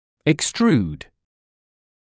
[ɪks’truːd][икс’труːд]экструдировать, пепемещать кнаружи, проводить экструзию (зуба)